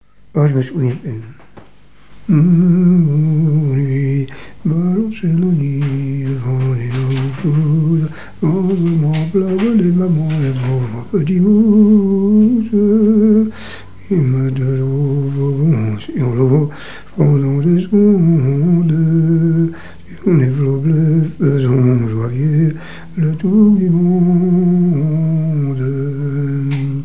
Air chantonné
témoignages et chansons maritimes
Pièce musicale inédite